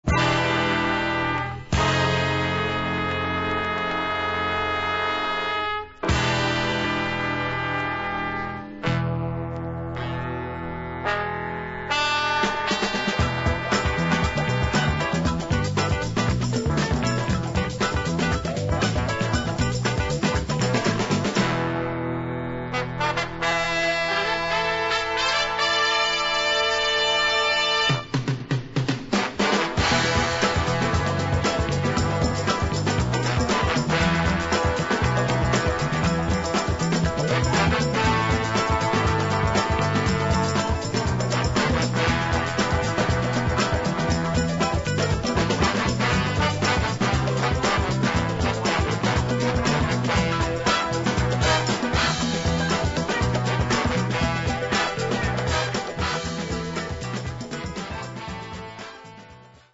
Possibly the best-known instrumental soundtrack cut.
An essential and enduring funk classic.